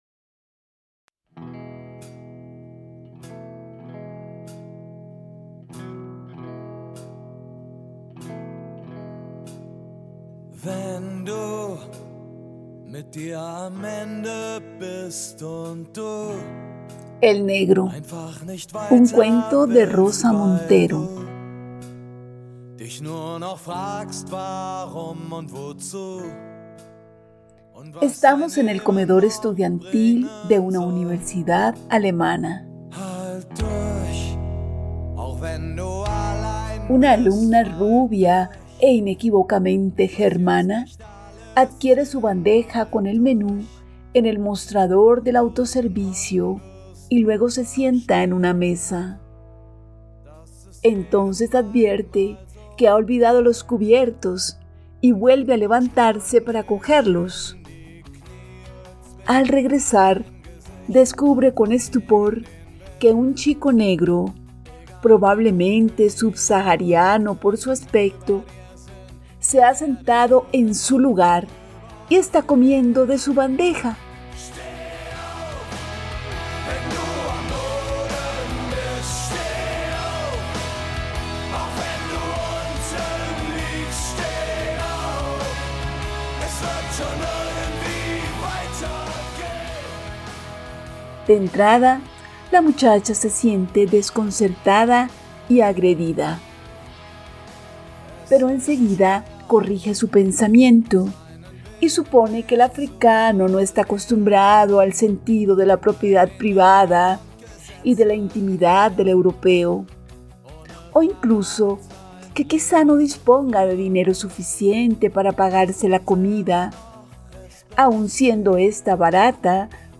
Música: “Steh auf, wenn du am Boden bist” [Levántate cuando estés en el suelo] del grupo alemán Die Toten Hosen.